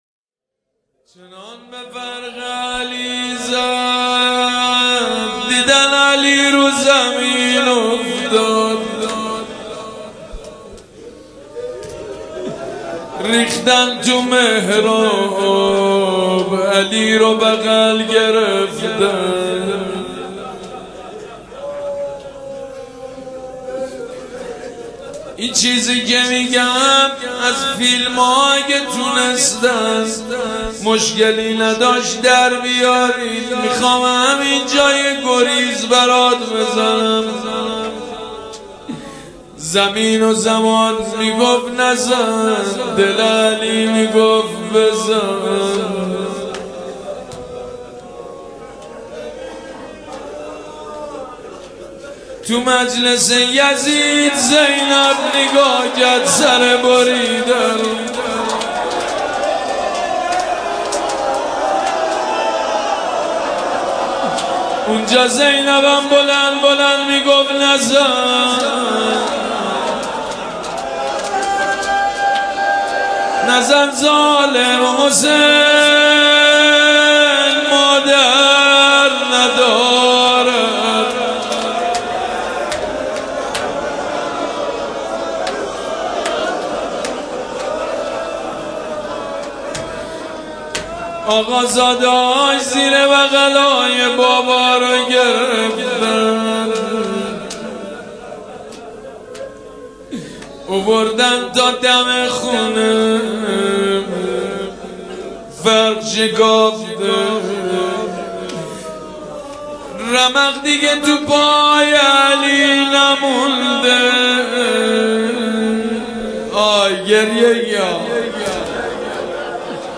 روضه و توسل ویژه شهادت امیرالمؤمنین(ع) به نفس سیدمجید بنی فاطمه -(موهام رو شونه کنید...)
مداح سیدمجیدبنی-فاطمه